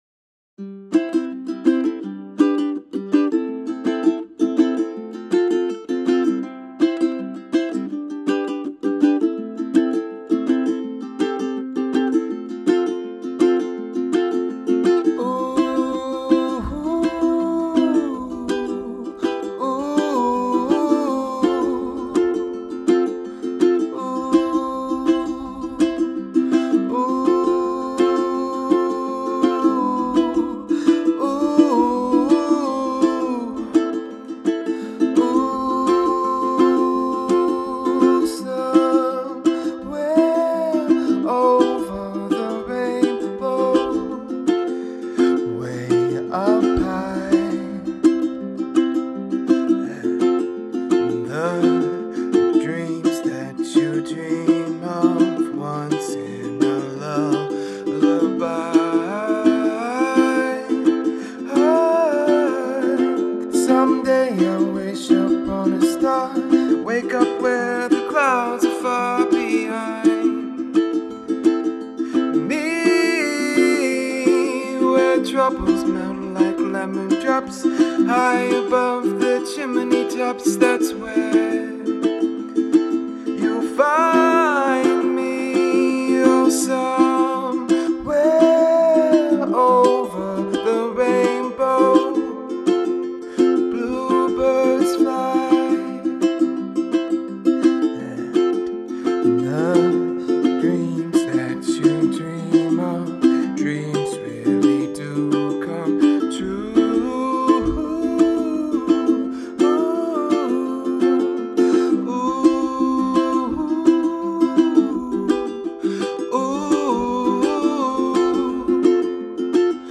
Vocals | Guitar | Trumpet | DJ